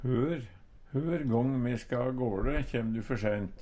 hør - Numedalsmål (en-US)